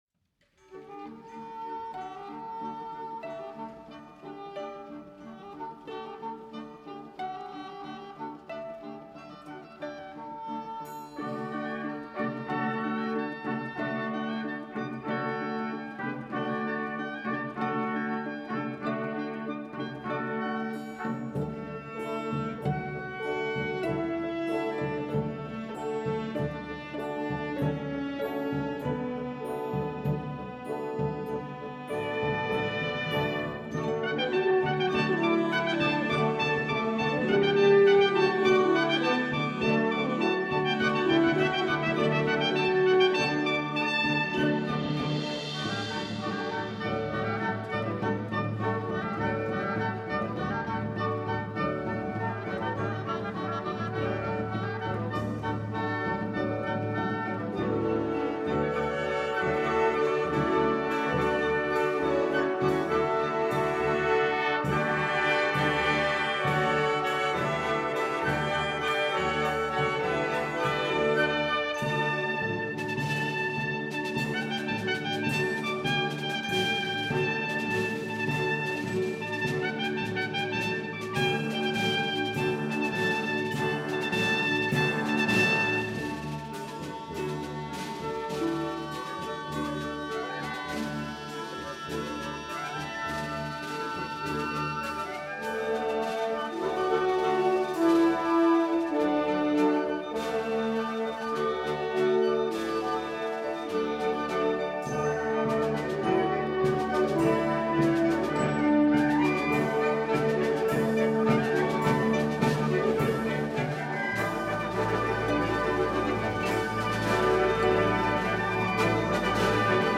Sinfonie